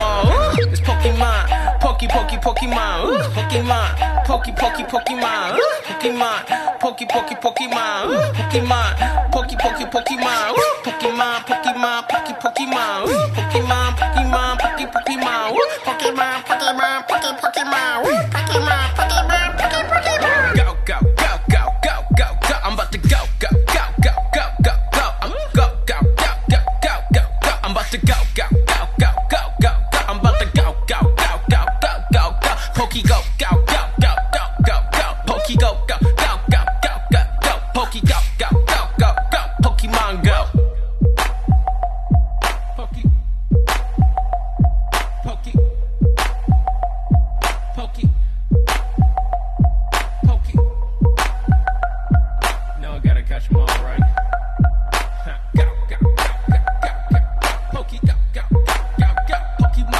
Pokémon Go pokeball sound effects free download